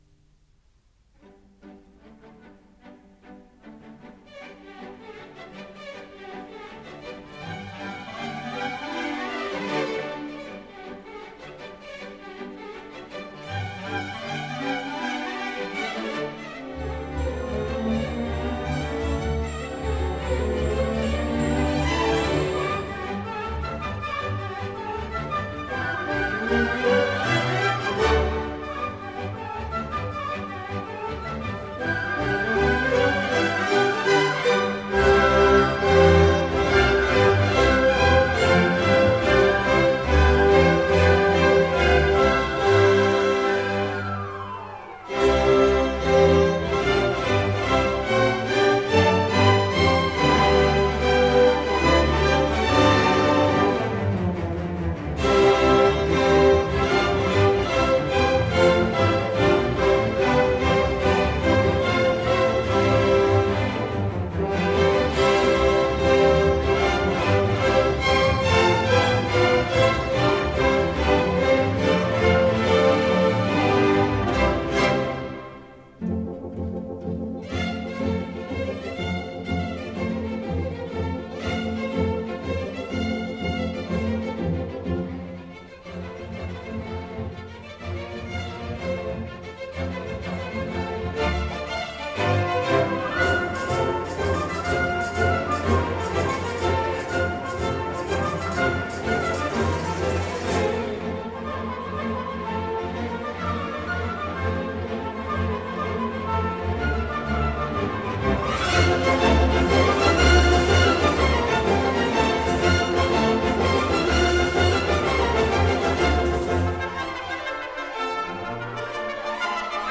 Ballet